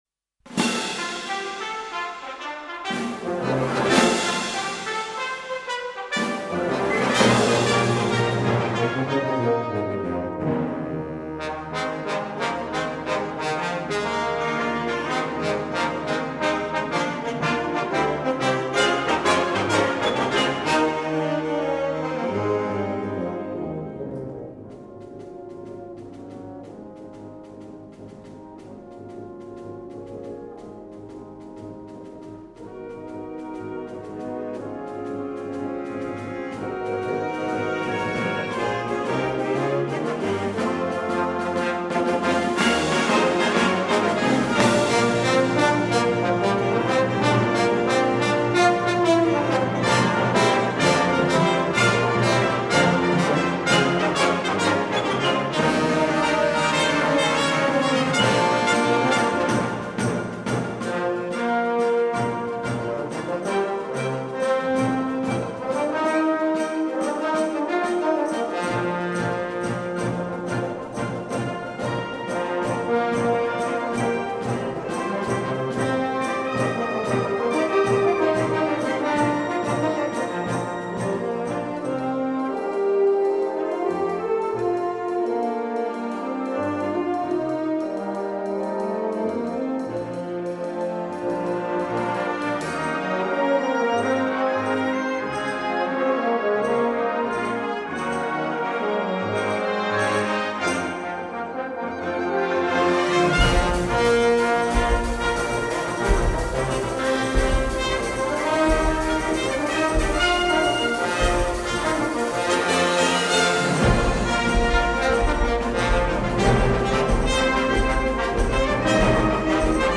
《急・緩・急》の連続して演奏する3各楽章構成のリズミカルで軽やかな音楽。